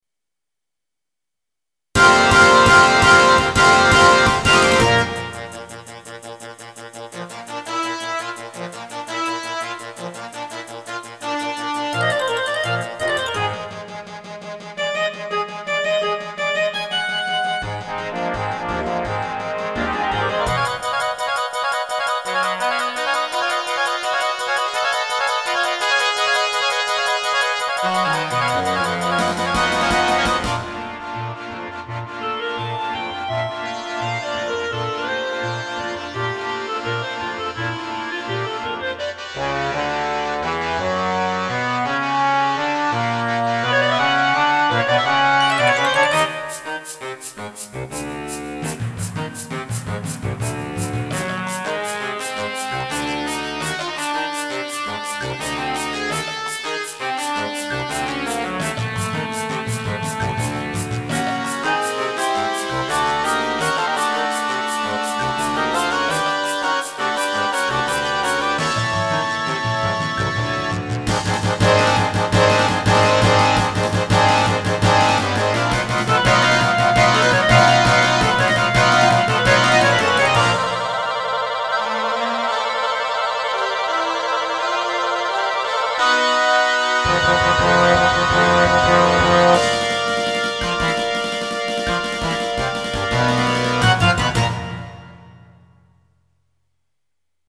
Instrumentation: Standard Concert Band